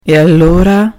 In this third example you are going to listen how to express your indifference: